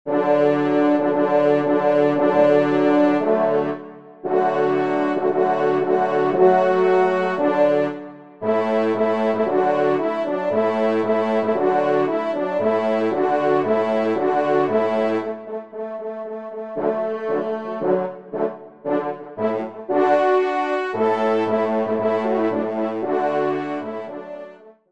Ensemble